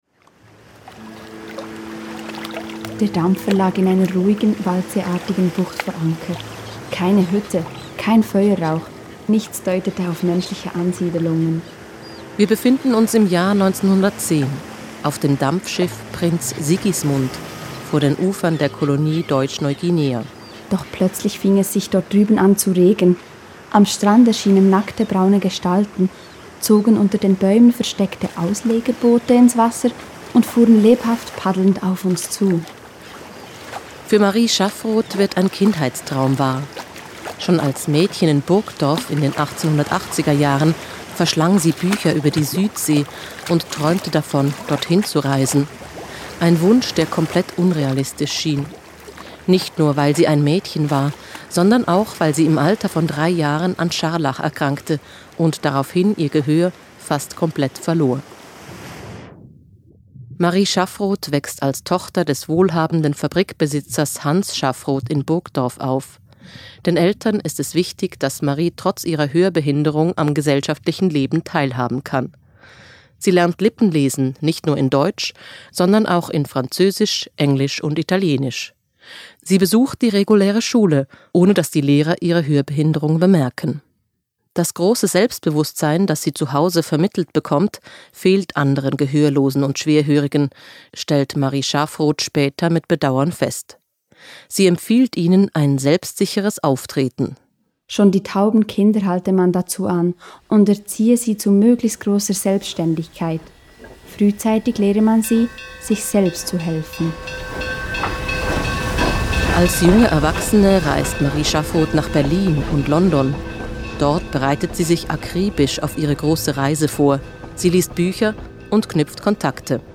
An den Hörstationen erzählen fünf Frauen aus ihrem Leben.